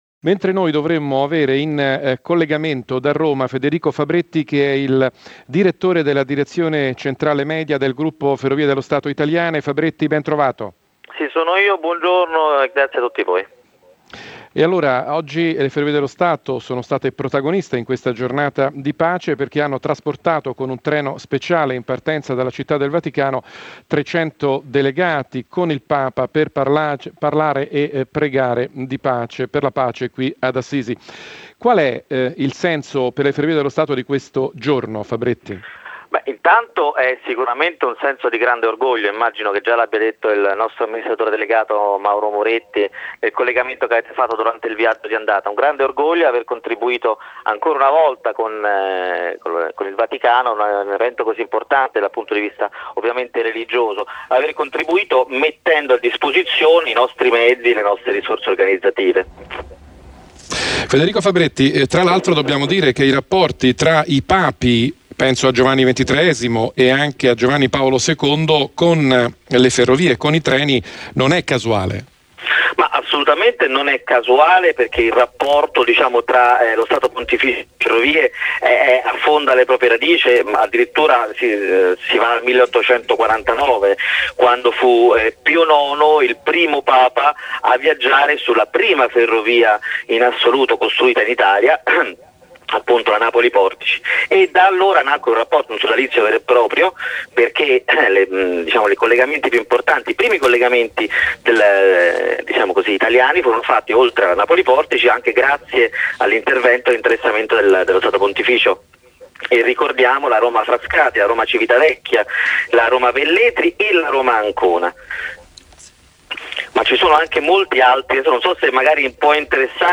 (nel podcast l'intervista completa)